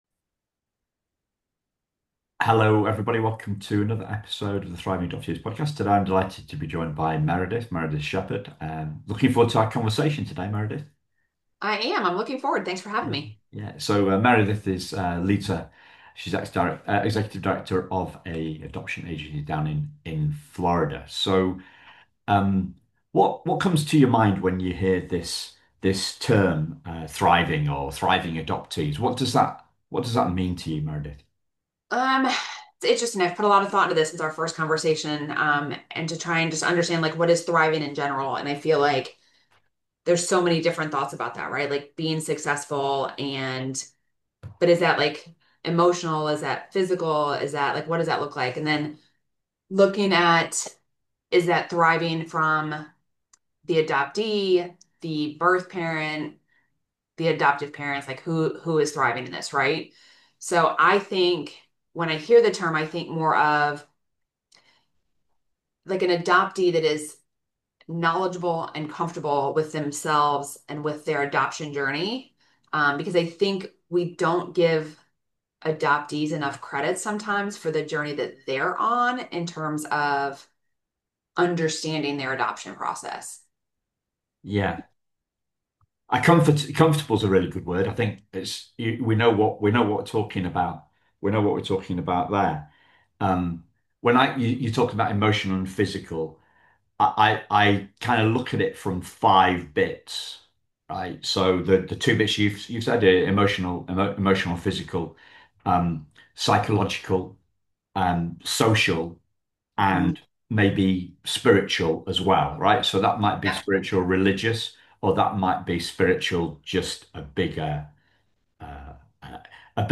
It's an incredibly uplifting interview from the youngest guest we've ever had on the show.